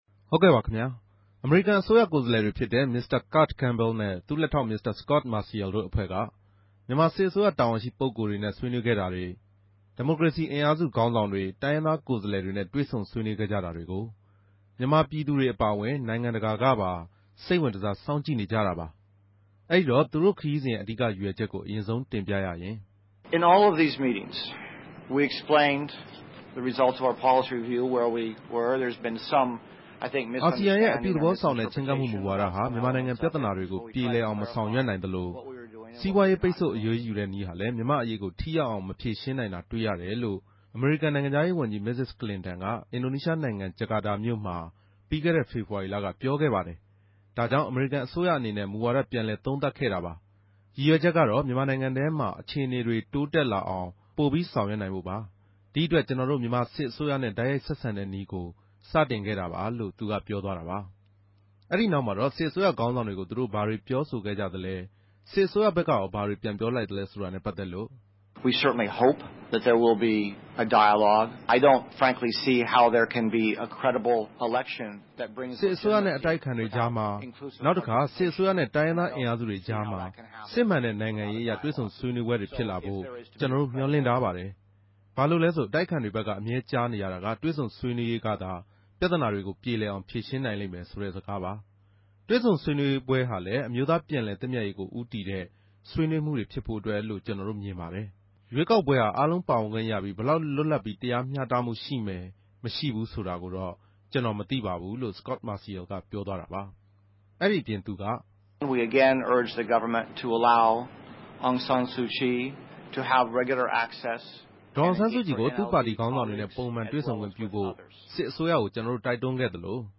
သတင်းစာရြင်းလင်းပြဲ။